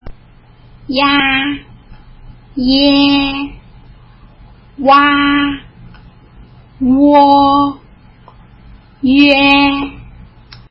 前の母音は軽く、後の母音を強く発音
ia ｢ィヤァ｣と発音するイメージ。
ie ｢ィエ｣と発音するイメージ。
ua ｢ゥア｣と発音するイメージ。
uo ｢ゥオ｣と発音するイメージ。
üe ｢ュィエ｣と発音するイメージ。